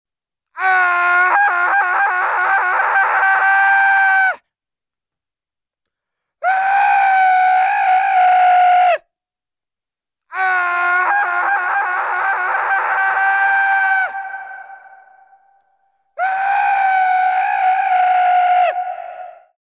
Звуки мужского крика
Панический вопль мужчины